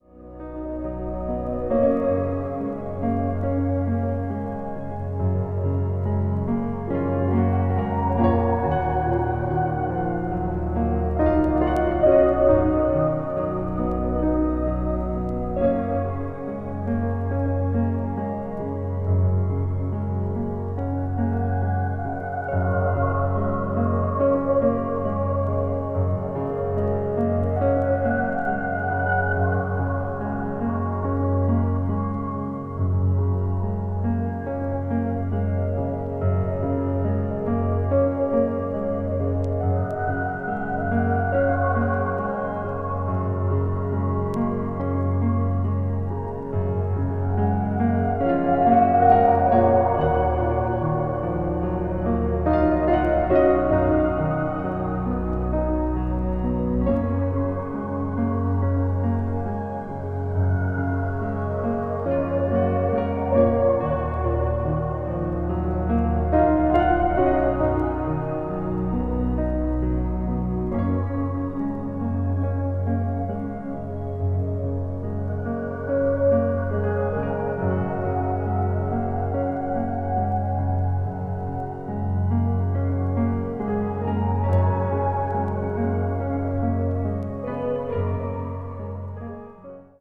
media : EX-/EX-(わずかなチリノイズ/一部軽いチリノイズが入る箇所あり)